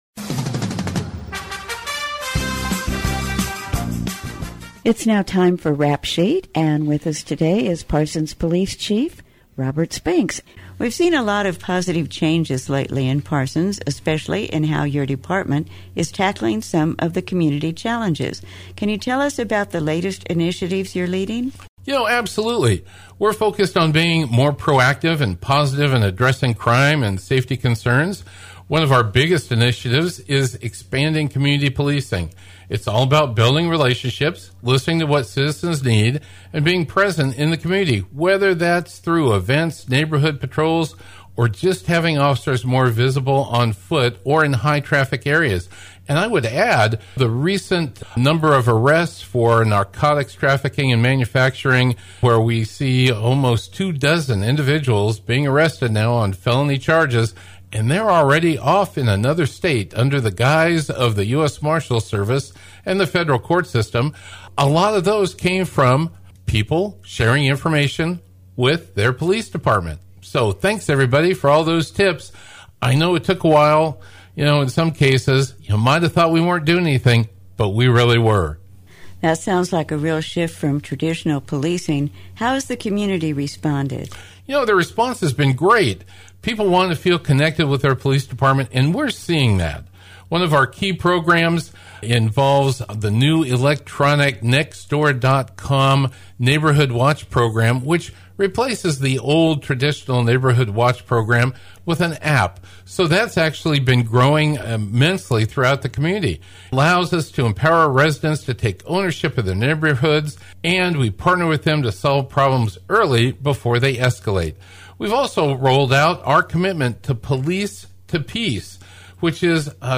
The Rap Sheet will be a reoccurring Podcast with the Parsons Police Chief Robert Spinks, and occasional guests, covering a variety of topics with the community and letting citizens know about upcoming events activities and information. The 'Rap Sheet' is aired on Wednesdays at 7:30am on 106.7 FM and 1540 AM V93KLKC.